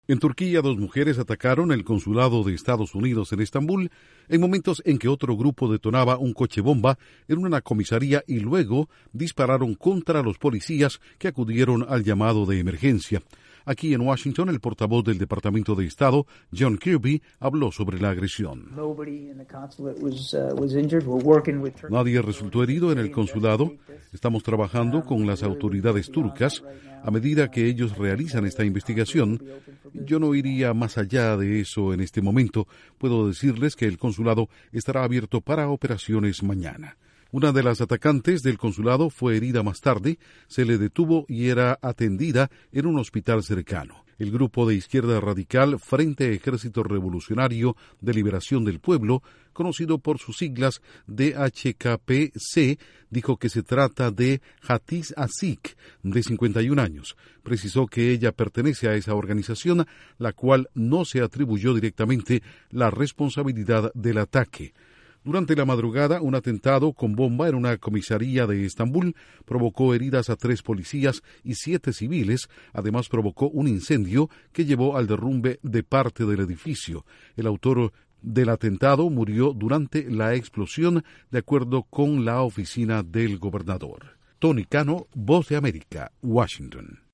Atacan el consulado de Estados Unidos en Estambul, durante una ola de atentados en Turquía. Informa desde la Voz de América en Washington